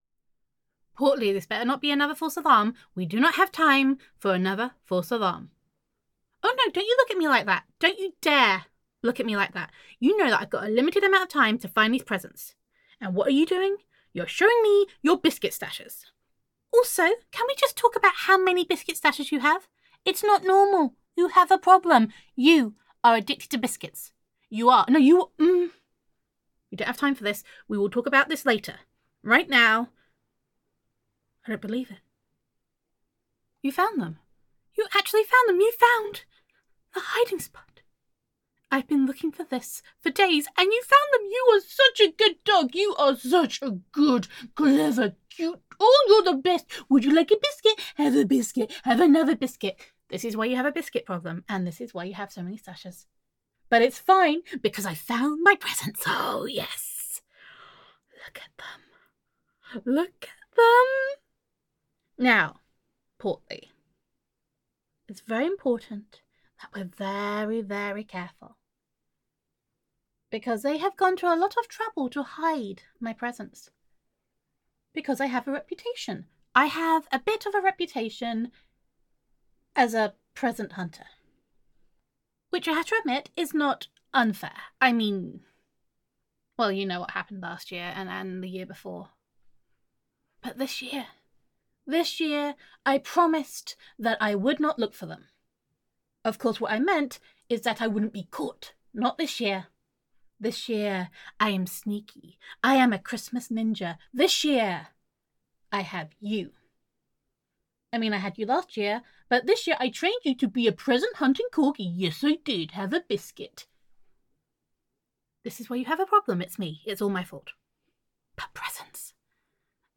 NEW GIRLFRIEND AUDIO 🎄 Your Girlfriend Promised Not to Hunt for Her Christmas Presents
[F4A] Portly the Present Hunting Corgi